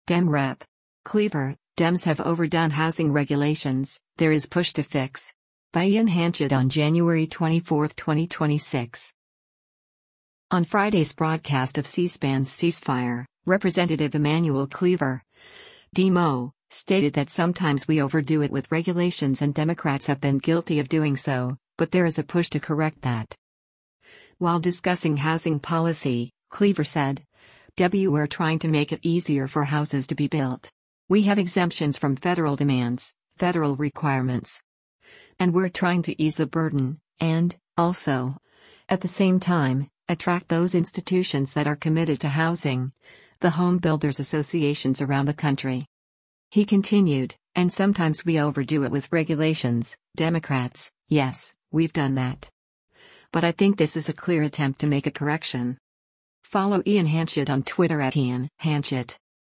On Friday’s broadcast of C-SPAN’s “Ceasefire,” Rep. Emanuel Cleaver (D-MO) stated that “sometimes we overdo it with regulations” and Democrats have been guilty of doing so, but there is a push to correct that.